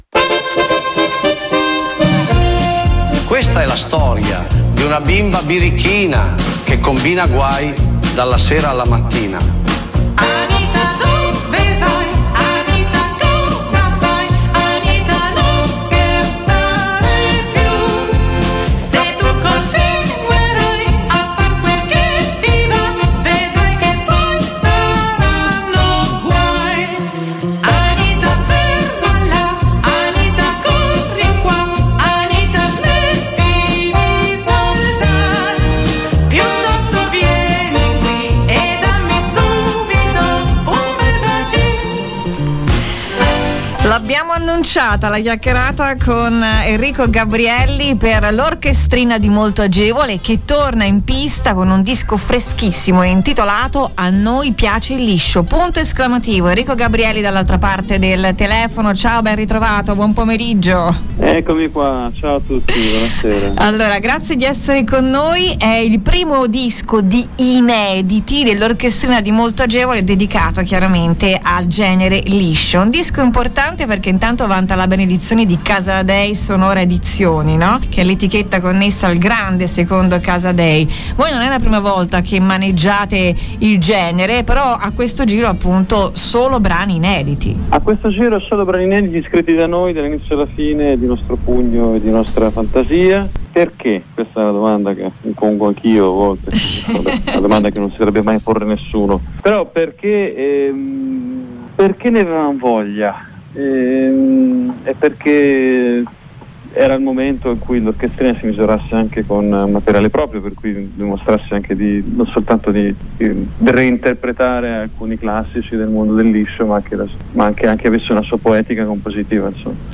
🎧 "A noi piace il liscio!" L'intervista con Enrico Gabrielli